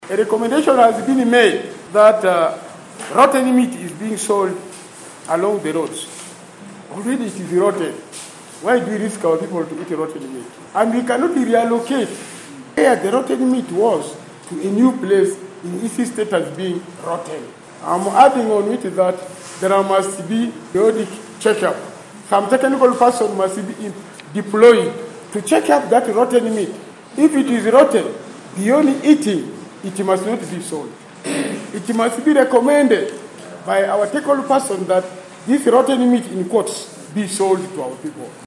In a recent council sitting on Thursday, September 28th, 2023, the Arua Central Division Council in Arua City has taken a strong stance against traders who have been selling expired goods, particularly rotten meat, in the area.
Hon Samsam Alia, the secretary for Gender and Development Services, passionately addressed the council during the meeting.